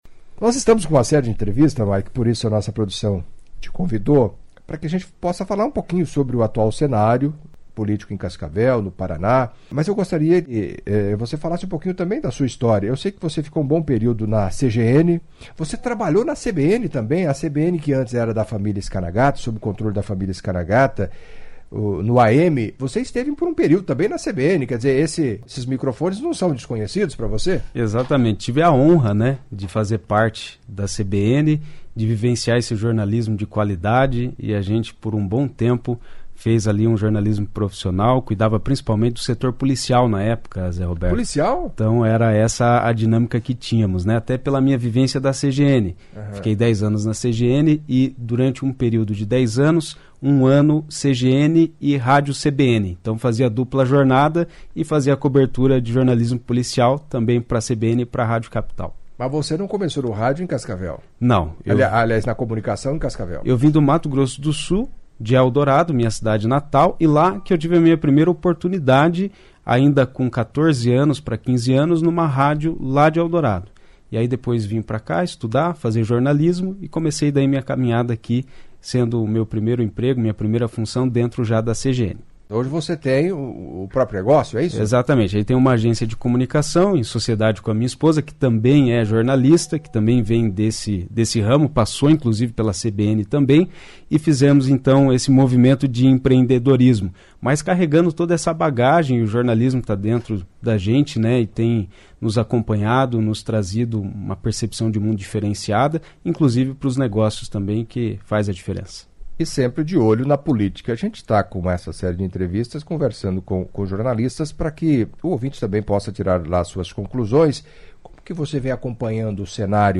Em entrevista à CBN nesta quinta-feira (26), na série de entrevistas com jornalistas de Cascavel